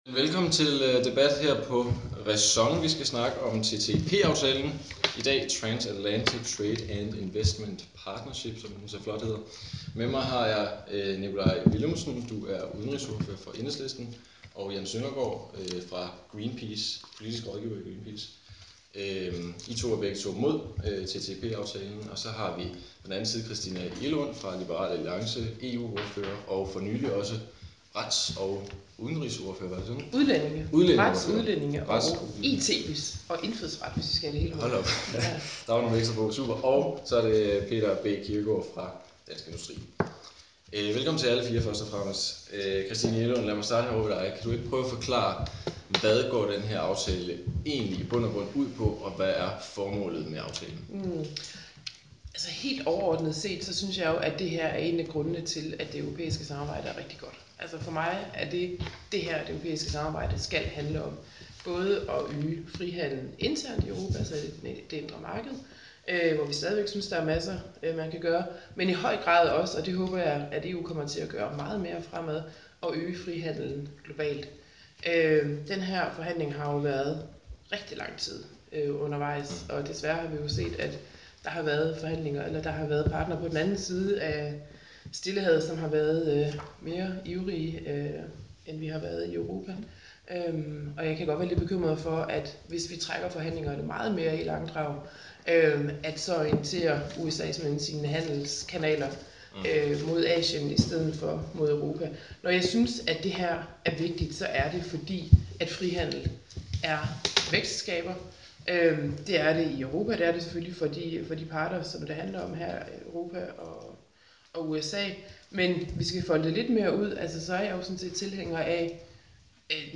Rundbord om TTIP – hvad står på spil for EU?: RÆSON samler Enhedslisten, Greenpeace, Liberal Alliance og Dansk Industri